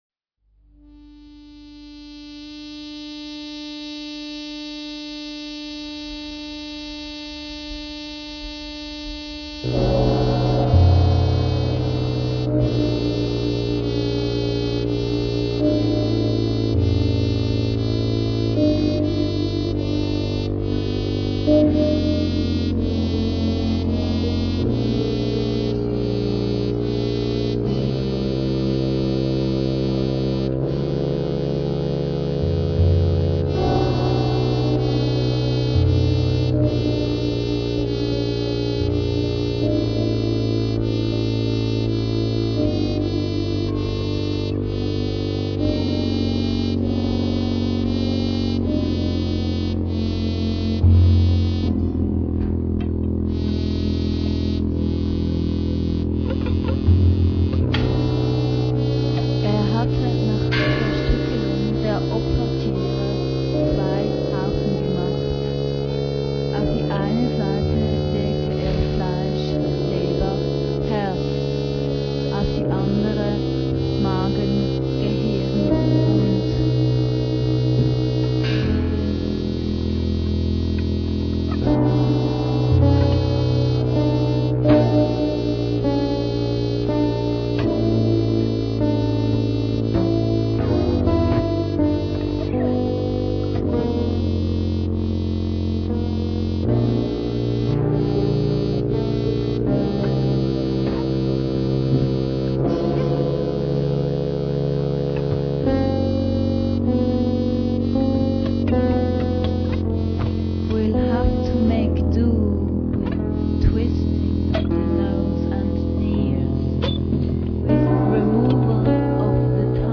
Definitely a bit dirty, lavatorial.
A music of feelings like some old out of time whisky.